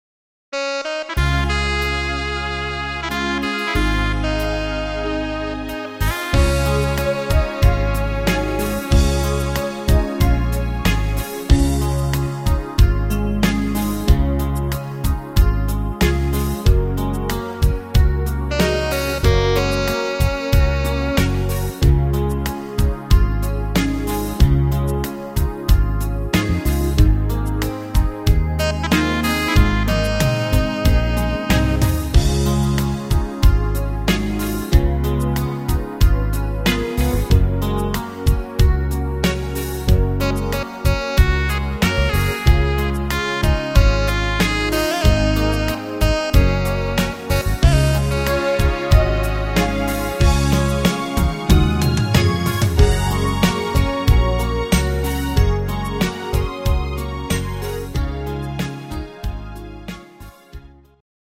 Rhythmus  Beguine
Art  Volkstümlich, Deutsch